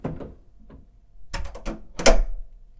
ドア閉める内側から
cls_hotel_door1.mp3